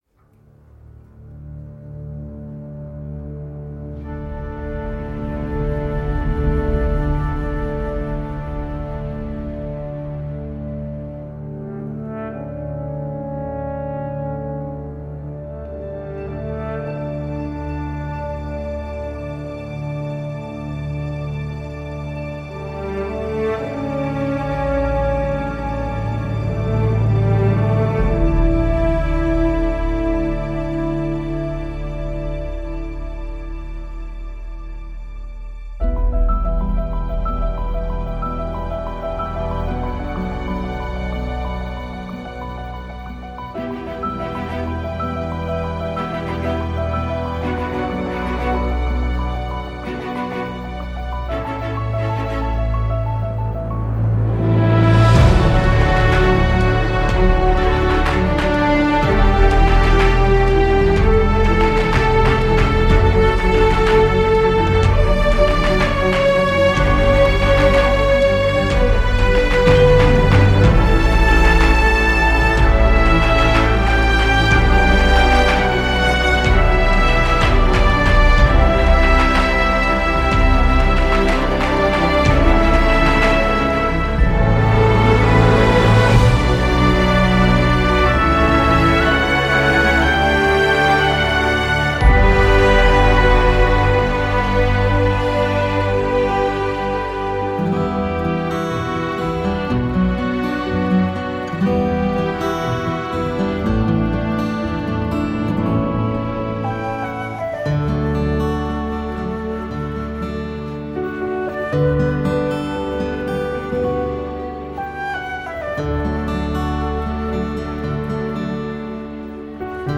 Un score un peu à l’ancienne, ce qui ne gâche rien.